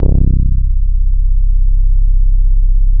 95 BASS   -L.wav